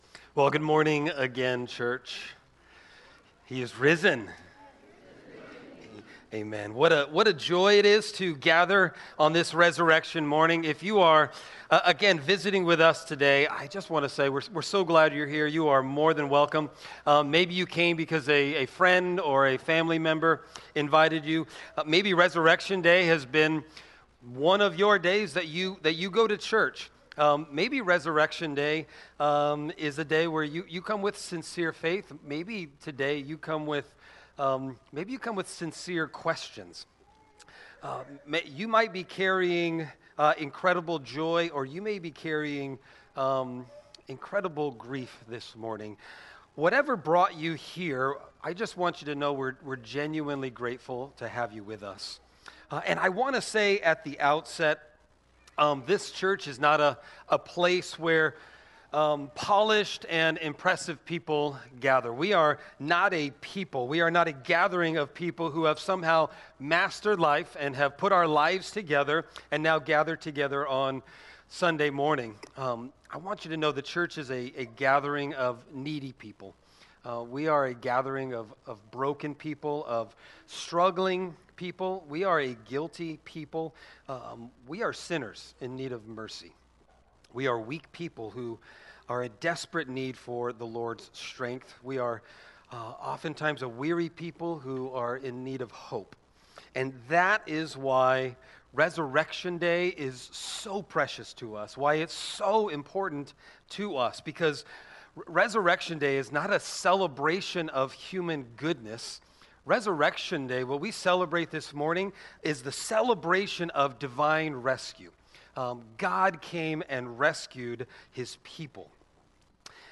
Sermon Text: 1 Corinthians 15:20-26